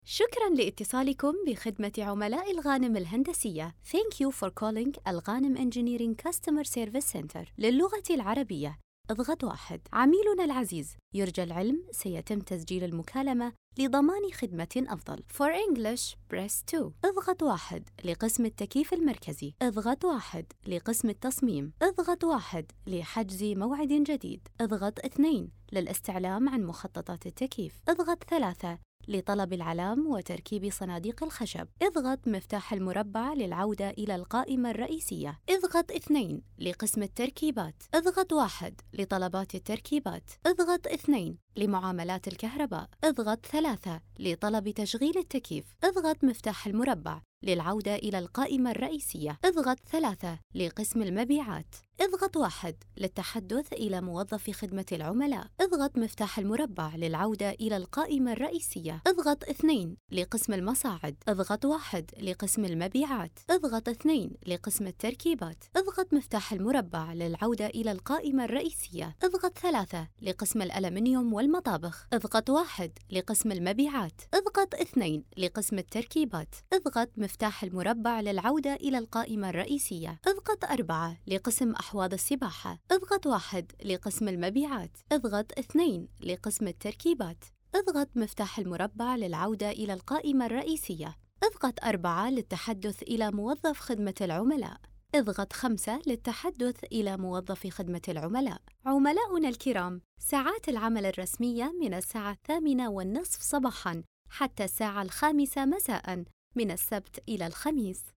الرد الآلي لشركة الغانم الهندسية